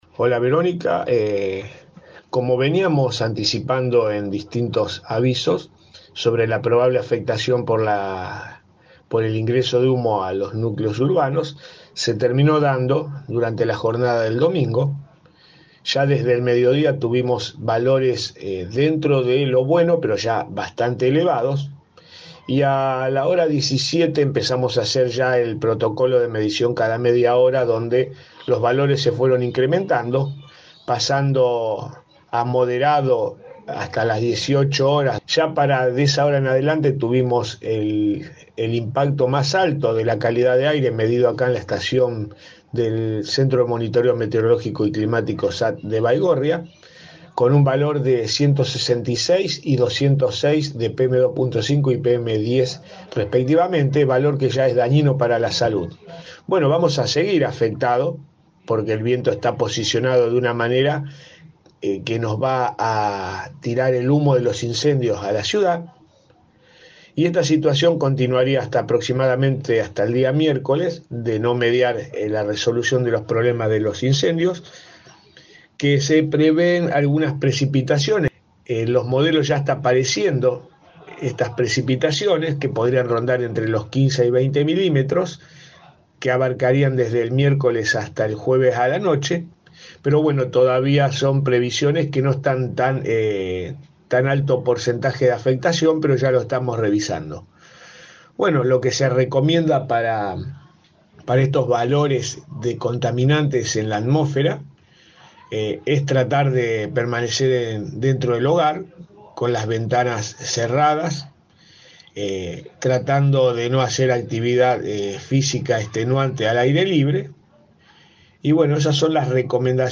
del Centro de Monitoreo Meteorológico y Climático SAT en diálogo con Primera Plana de Cadena 3 Rosario “los niveles más altos se registraron pasadas las 18 horas de este domingo y la presencia de humo podría mantenerse con intermitencias hasta este miércoles”.